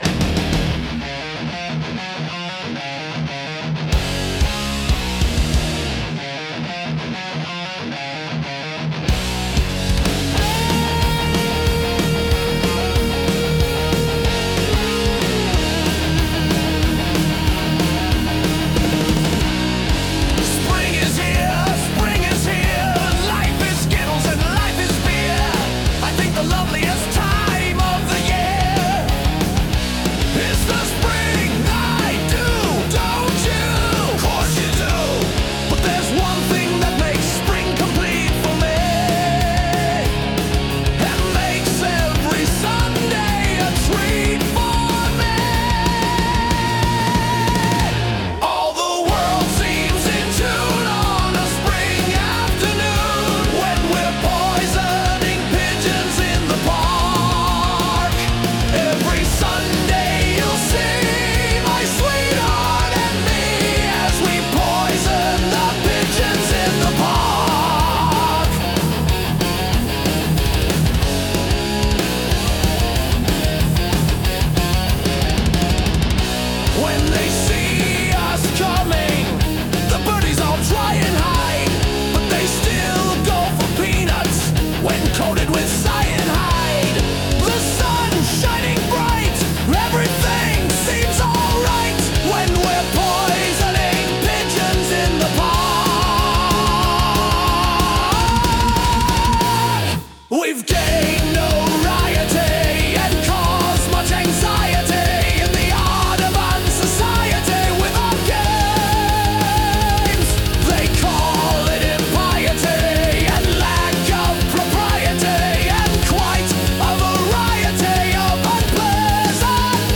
heavy metal